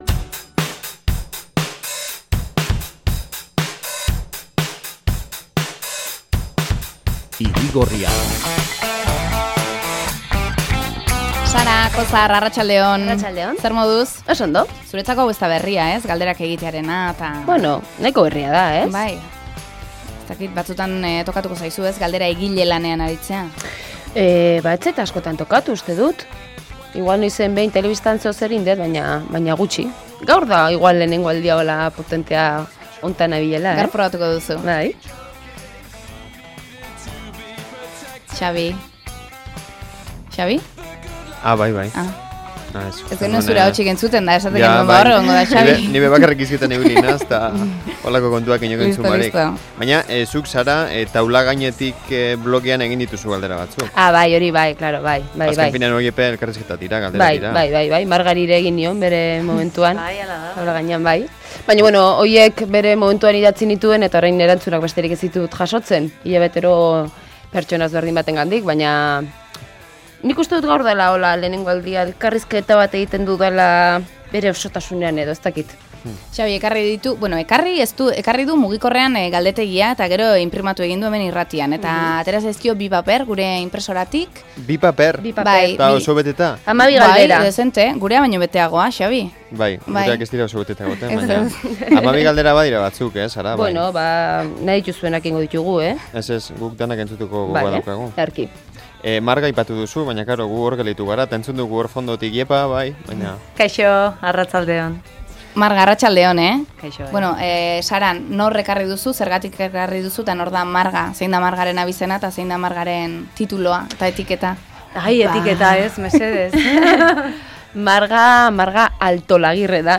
elkarrizketa | Euskadi Irratia